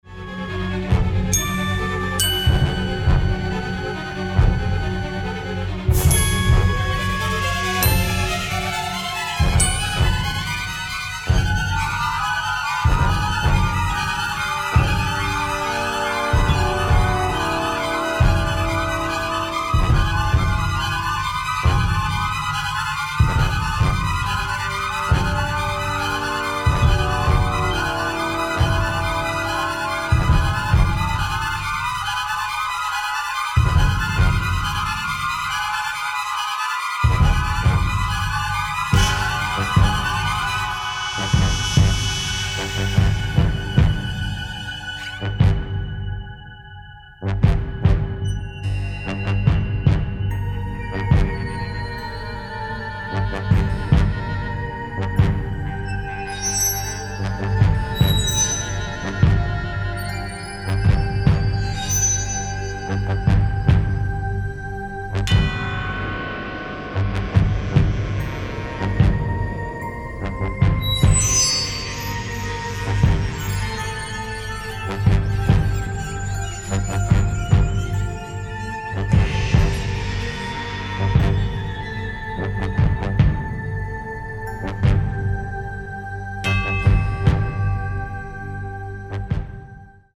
stereo presentation
original motion picture score